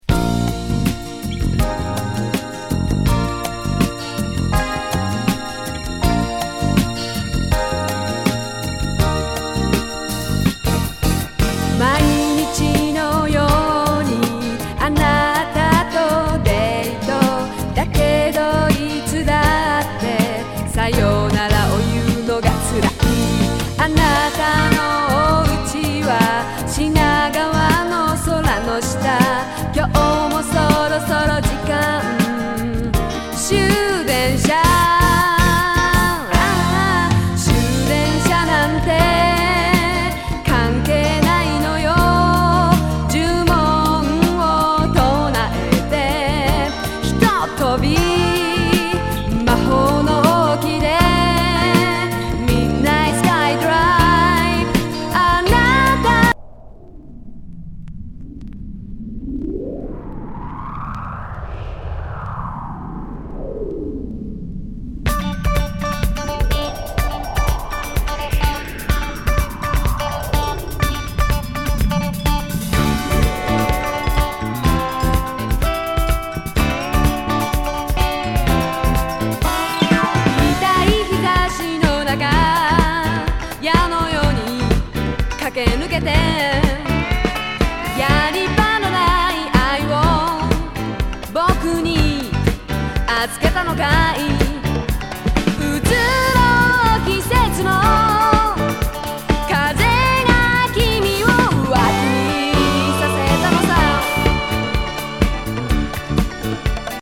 ロマンチック歌謡
グルーヴィ
フェンダーローズ・メロウ